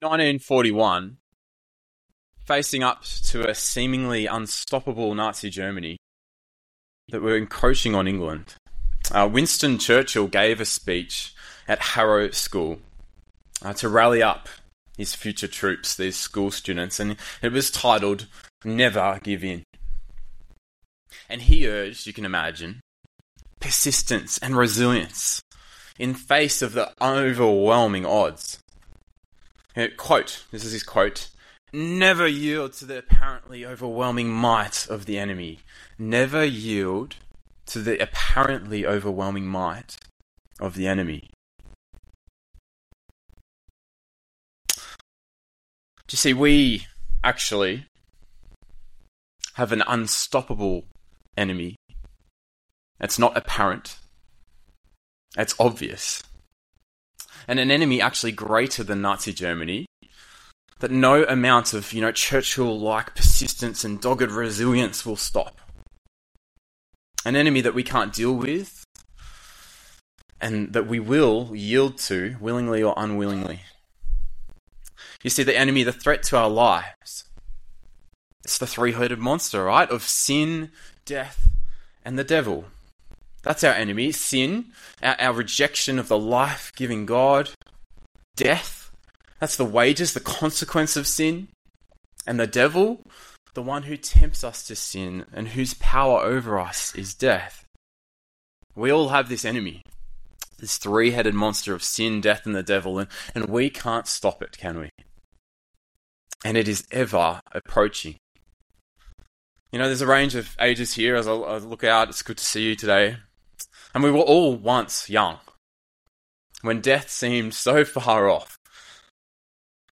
One-Off Sermons